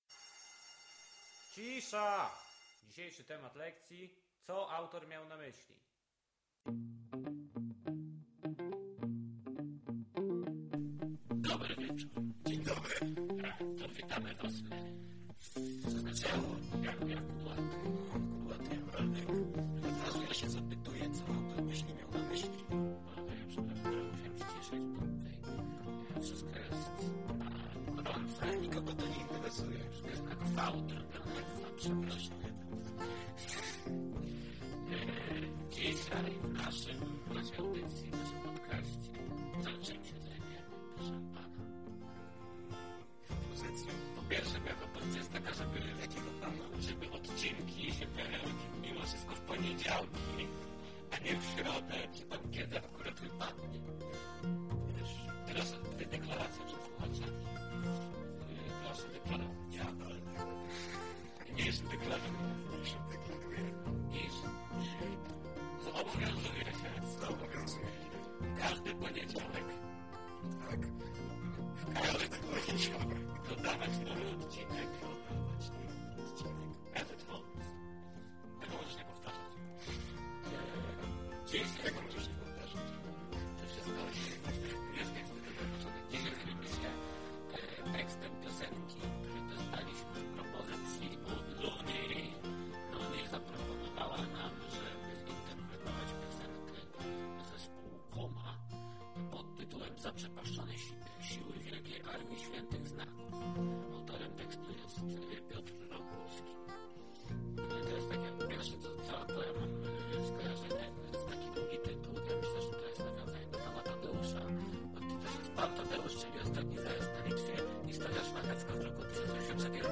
"Co Ałtor Miał na Myśli" to audycja rozrywkowa, nagrywana co tydzień lub dwa.